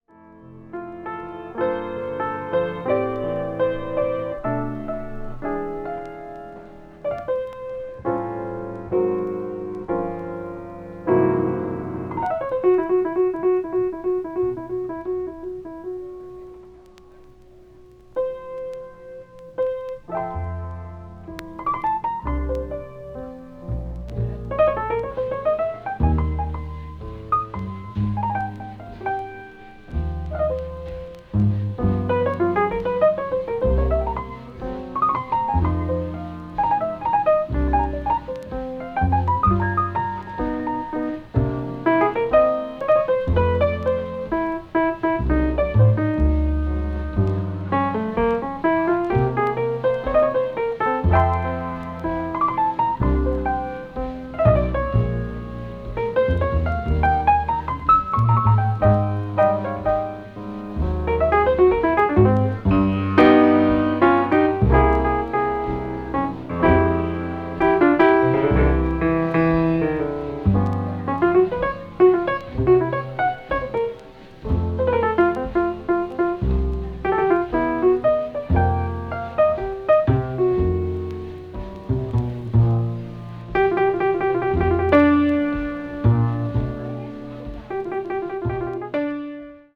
hard bop   modern jazz